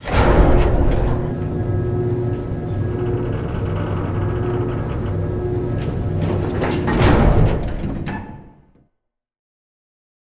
Moving.ogg